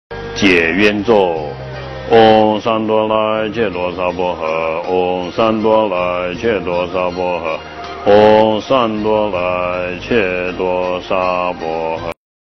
诵经
佛音 诵经 佛教音乐 返回列表 上一篇： 解毒神咒 下一篇： 绿度母心咒 相关文章 悲悲怜悯众生情--佛教音乐 悲悲怜悯众生情--佛教音乐...